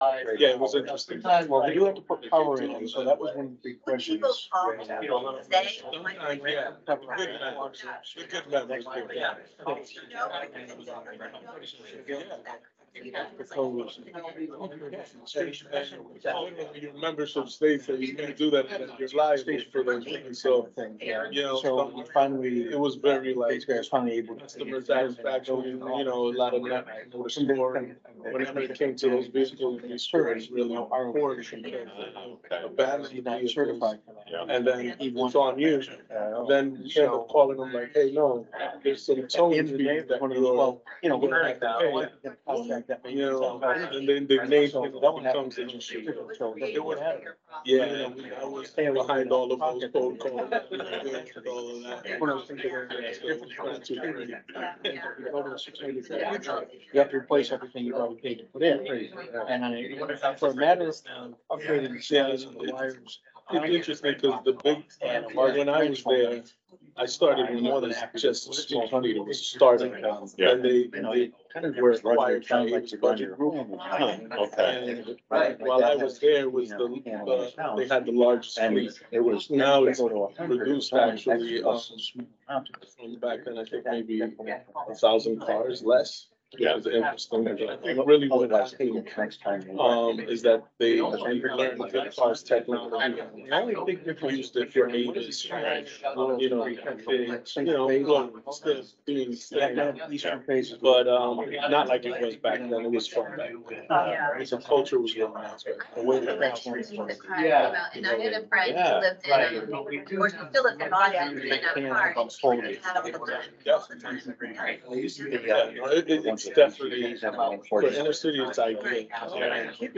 Meeting Recording